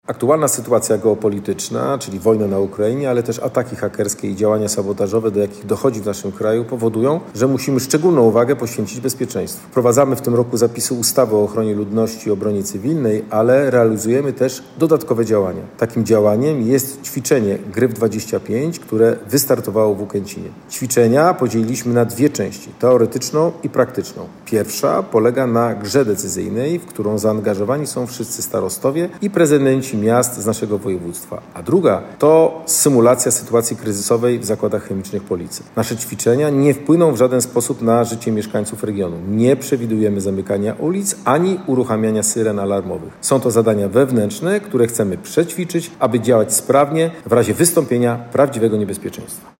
Adam Rudawski, Wojewoda Zachodniopomorski: